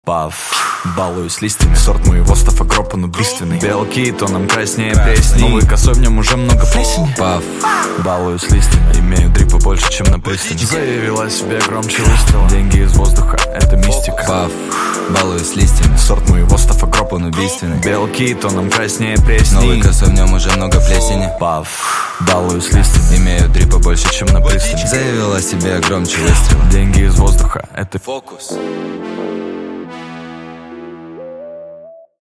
Громкие Рингтоны С Басами
Рэп Хип-Хоп Рингтоны